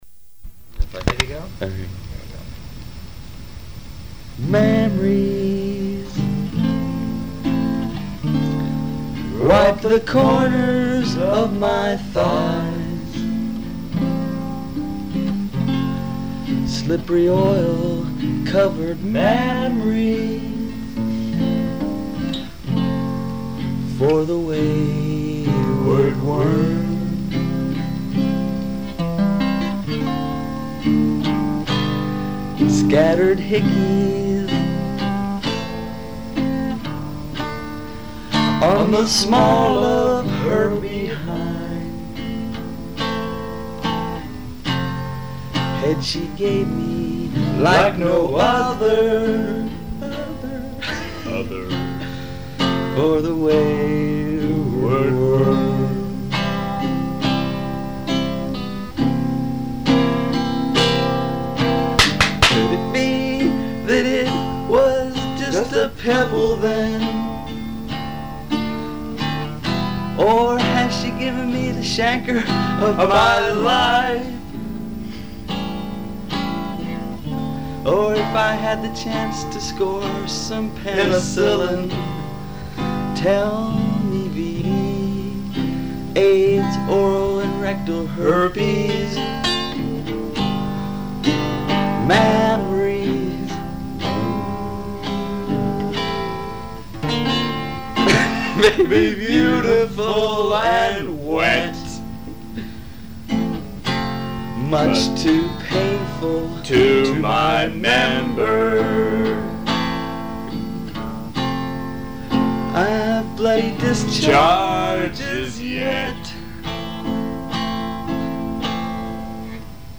decided to record into a tape recorder a version